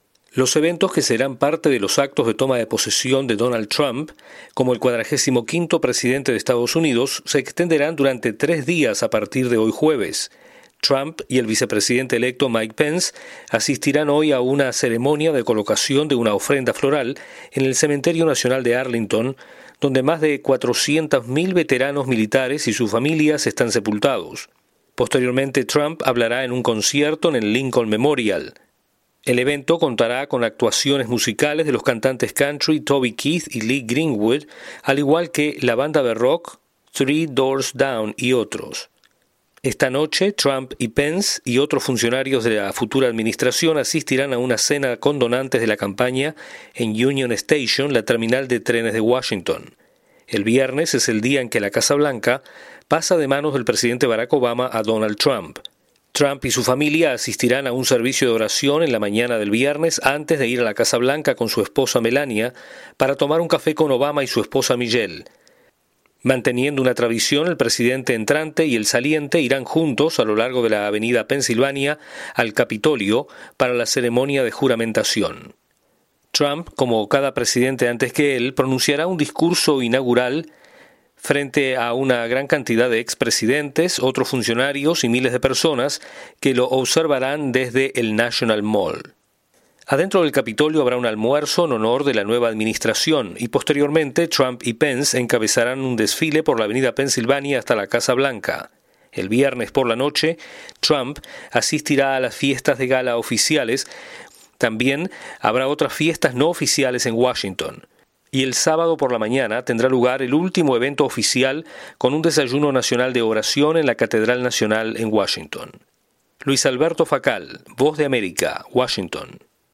Comienzan los actos por la toma de posesión presidencial de Donald Trump. Desde la Voz de América en Washington informa